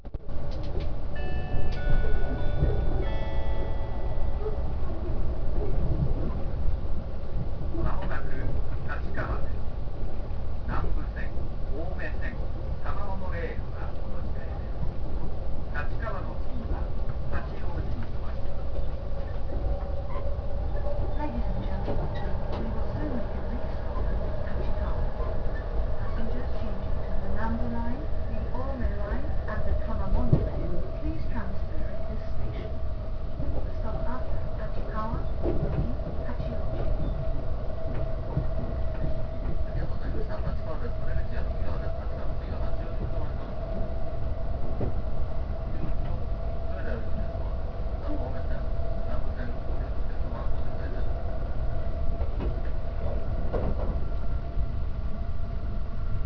・E257系車内放送
255系のように専用のチャイムがあるわけでもなく、JR東日本の特急型車両としてはお馴染みのチャイムと放送です。